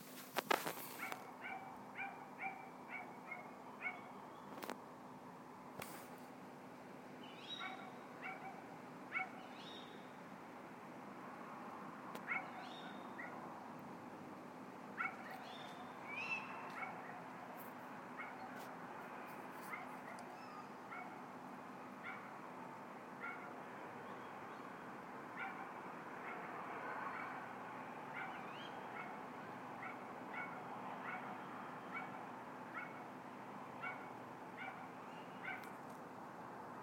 more owl. and dog.